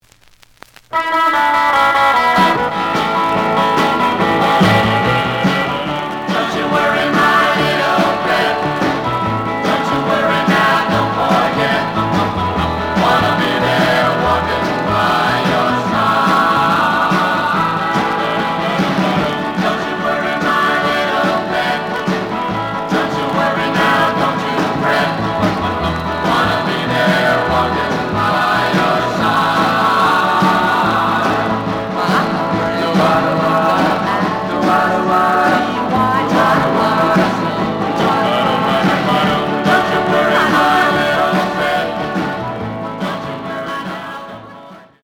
The audio sample is recorded from the actual item.
●Genre: Rock / Pop
Slight noise on both sides.